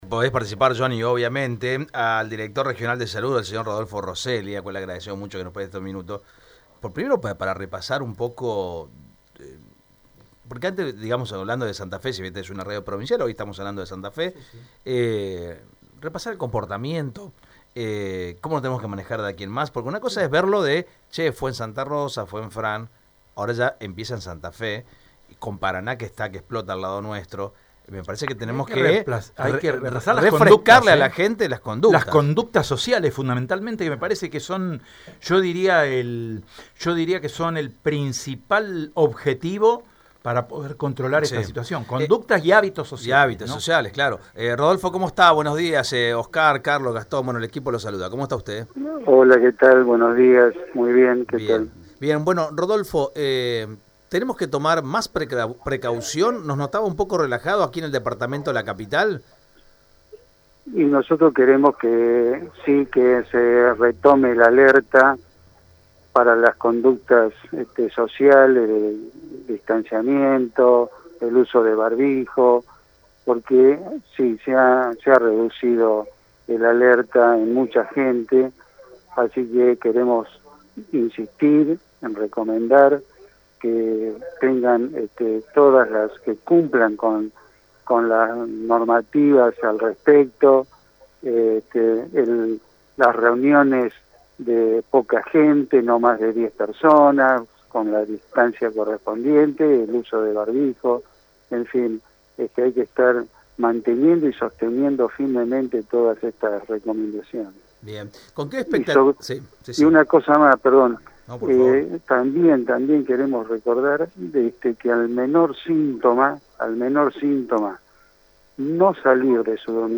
Rodolfo Roselli en Radio EME: "Queremos intensificar los controles fronterizos"
Al respecto, y ante la creciente confirmación de casos positivos, el Director Regional de Salud, Rodolfo Roselli, sostuvo en Radio EME que «nosotros queremos que se retome el alerta para las conductas sociales de distanciamiento, uso de barbijo y reuniones de no más de 10 personas, porque queremos que se cumplan con las normativas al respecto«.